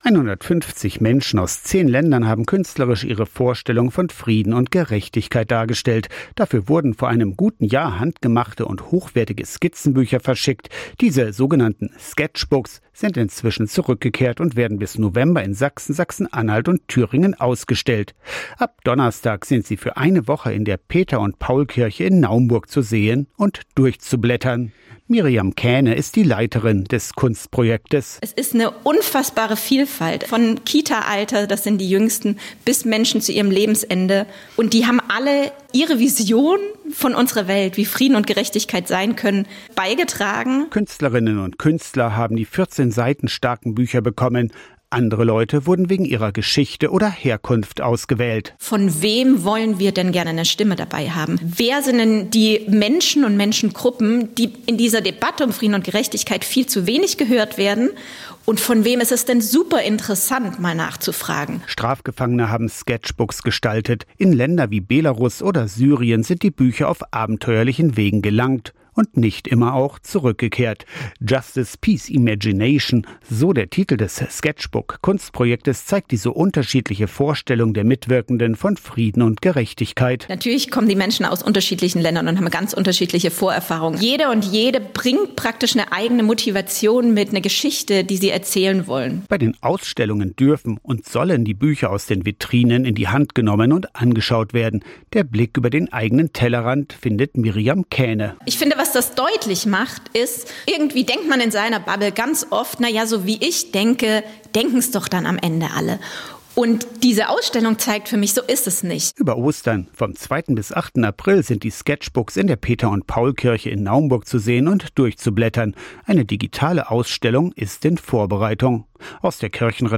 Ab Donnerstag sind sie für eine Woche in der Peter-und-Paul-Kirche in Naumburg zu sehen und durchzublättern. Kirchenreporter